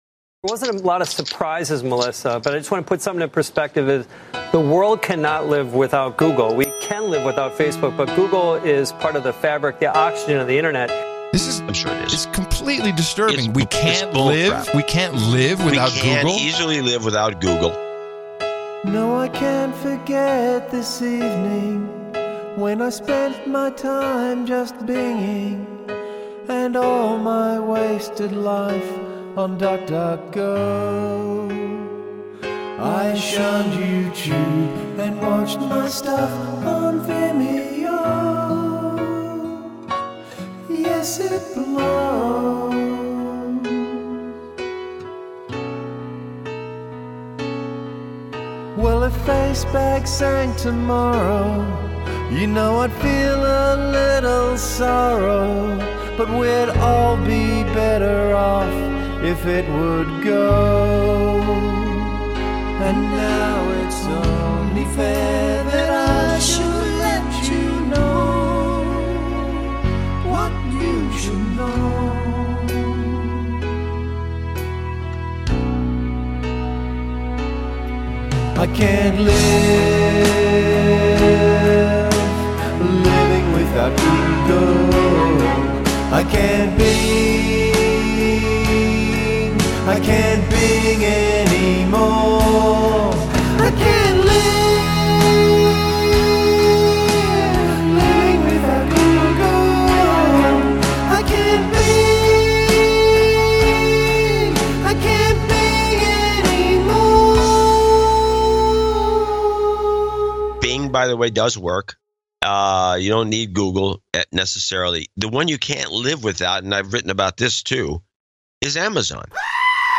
Pre show demo